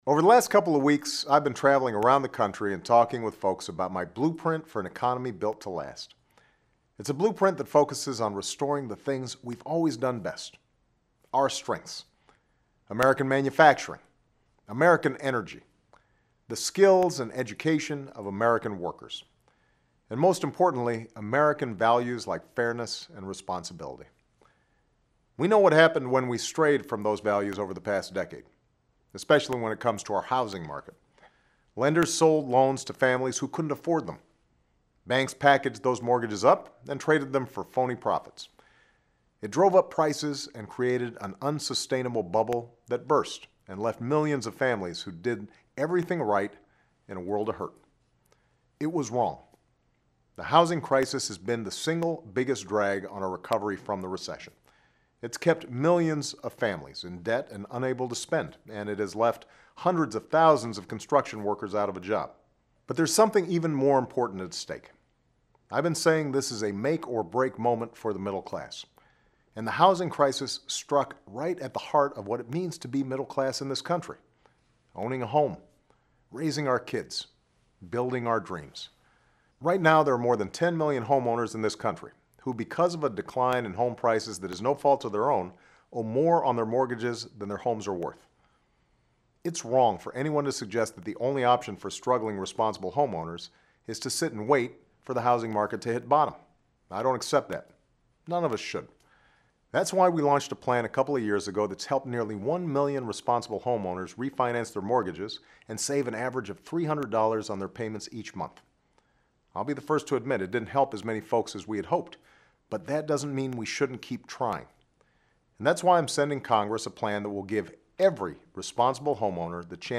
Remarks of President Barack Obama
美国总统奥巴马每周电台演讲